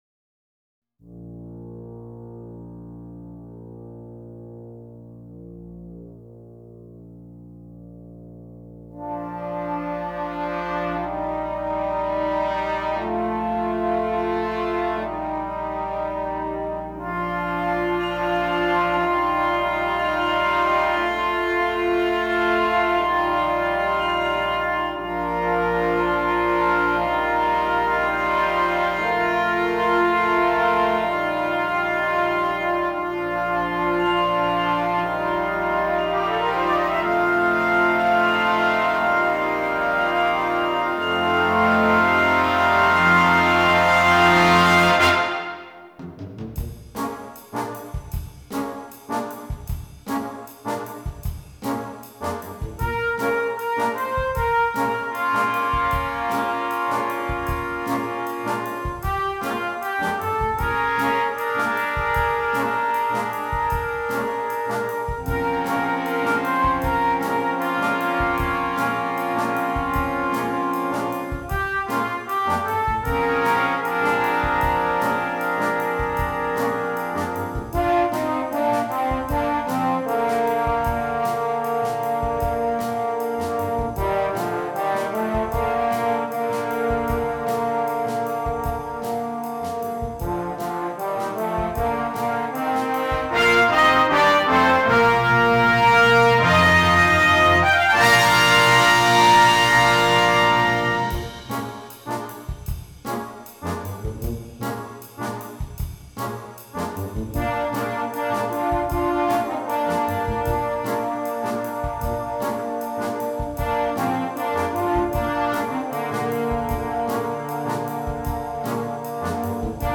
heading then to a triumphant ending.